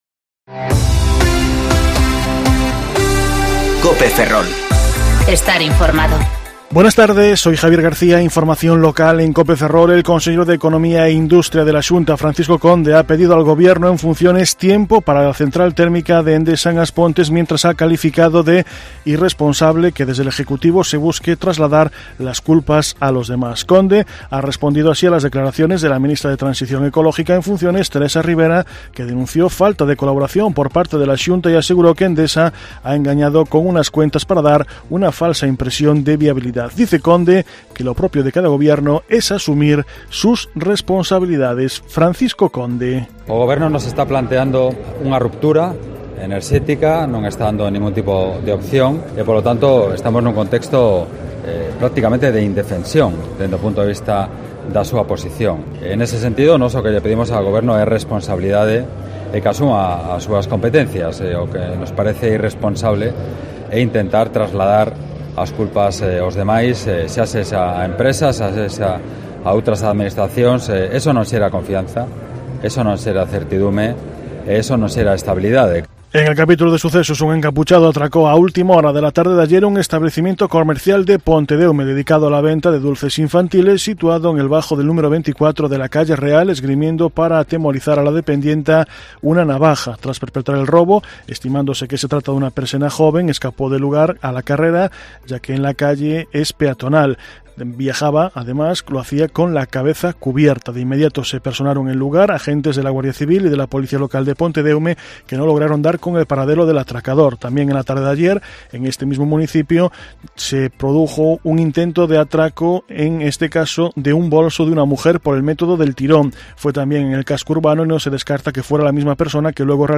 Informativo Mediodía Cope Ferrol 28/10/2019 (De 14.20 a 14.40 horas)